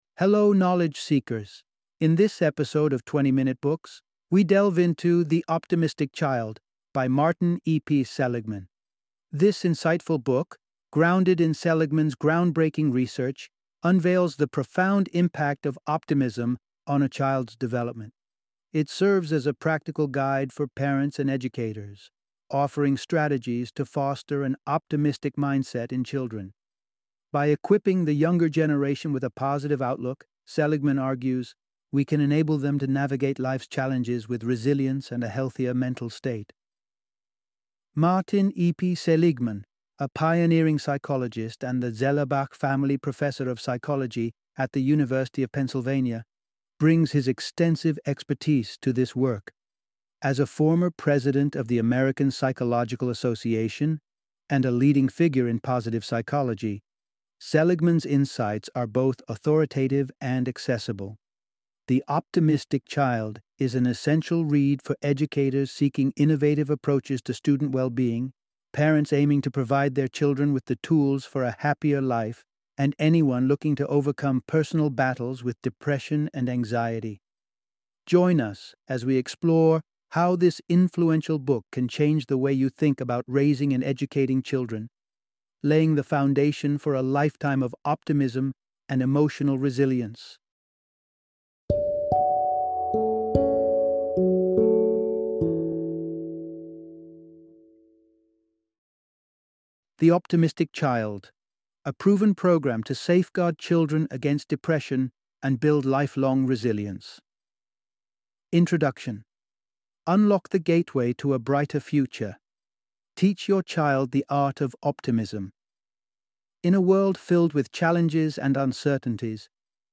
The Optimistic Child - Audiobook Summary